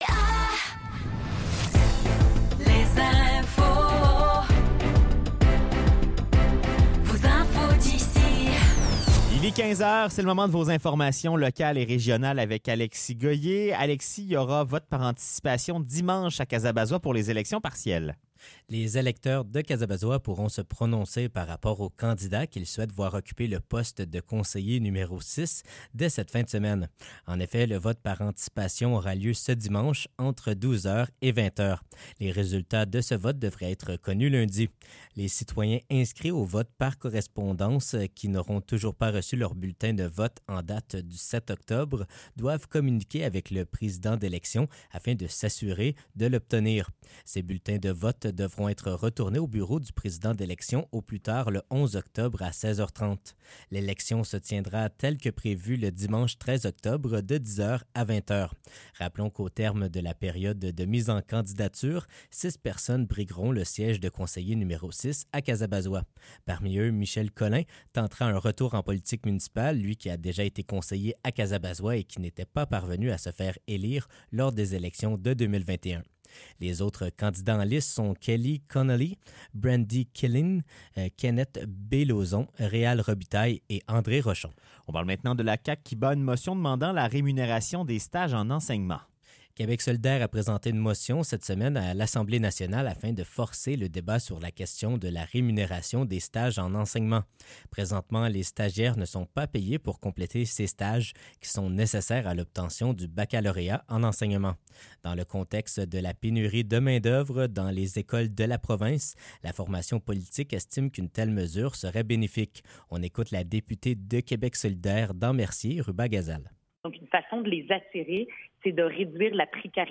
Nouvelles locales - 4 octobre 2024 - 15 h